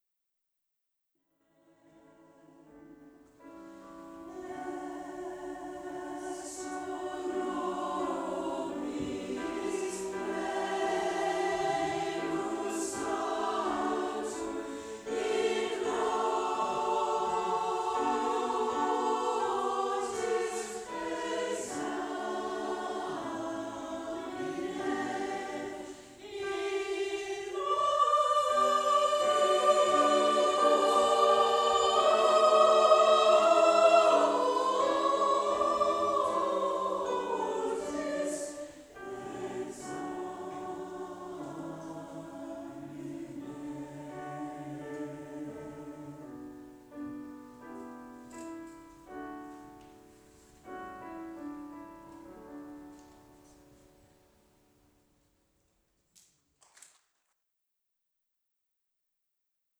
We are one of the largest mixed gender choirs in the area
A selection of audio excerpts from various performances by the Barry Community Choir, recorded at venues across South Wales.
Ave-Verum-LIVE.wav